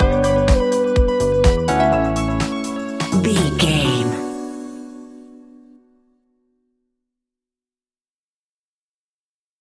Aeolian/Minor
groovy
energetic
hypnotic
electric guitar
drum machine
synthesiser
electric piano
bass guitar
funky house
deep house
nu disco